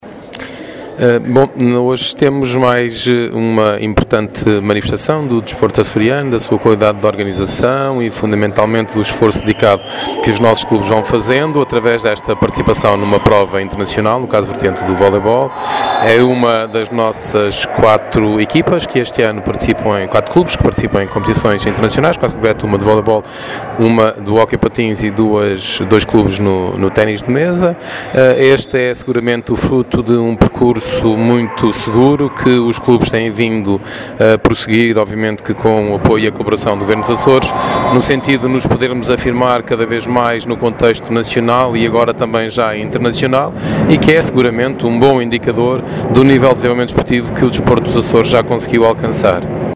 A afirmação é do Diretor Regional do Desporto, falando à margem do jogo de voleibol entre a Associação de Jovens da Fonte do Bastardo e o Hurrikaani Loimaa da Finlândia, a que assistiu, a contar para a 1ª mão da 2ª eliminatória da “2013 Challenge Cup”, realizado ontem à noite na Praia da Vitória.